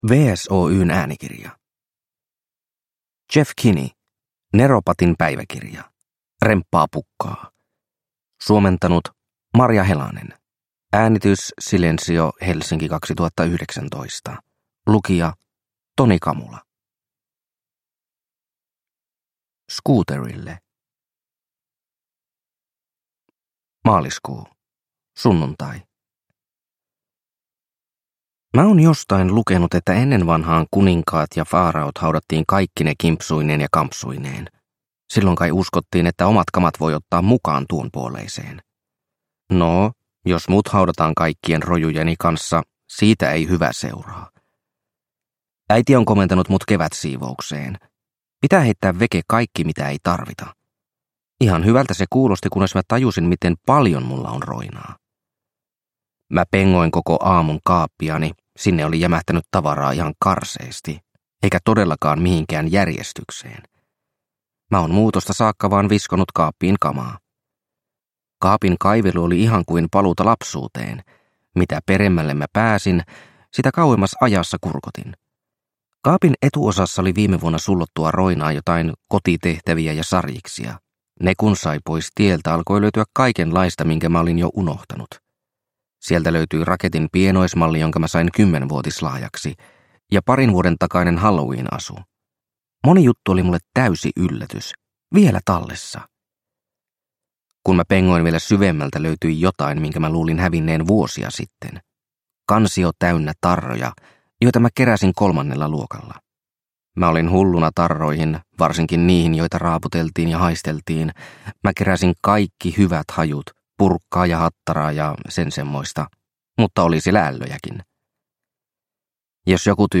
Neropatin päiväkirja: Remppaa pukkaa – Ljudbok – Laddas ner